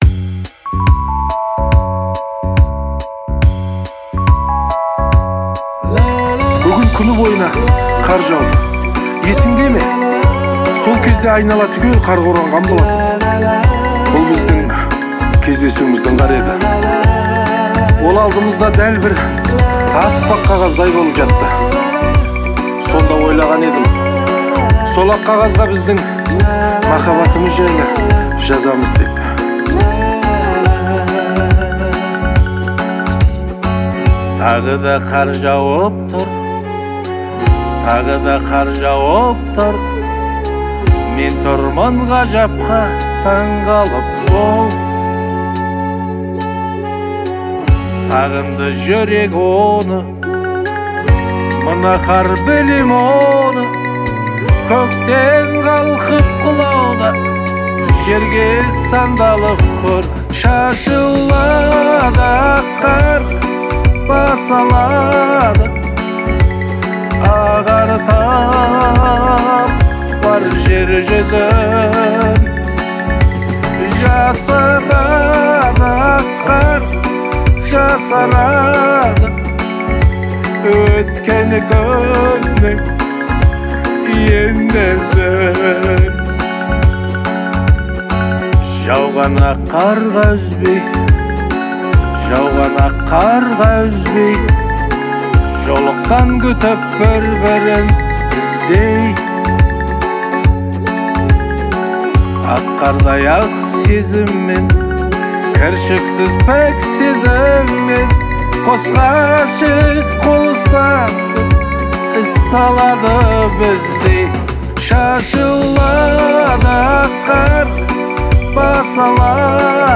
это трогательная казахская песня в жанре поп